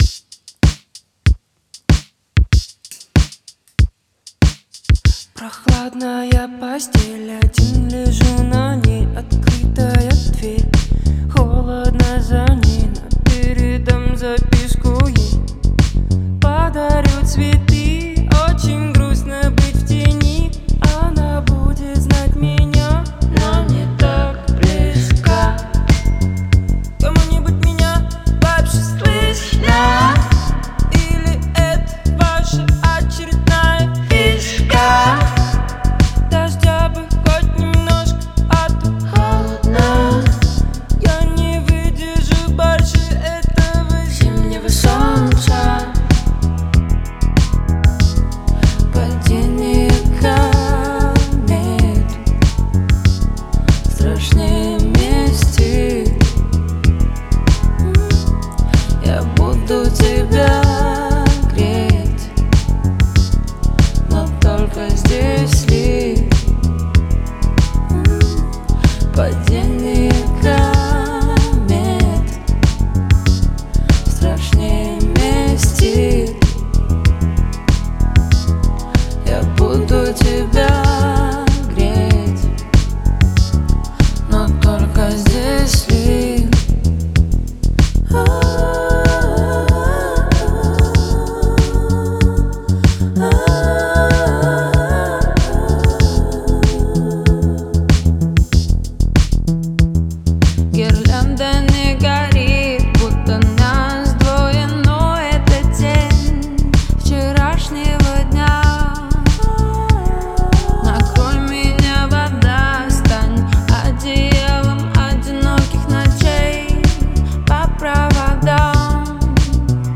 РусскаяИнди музыка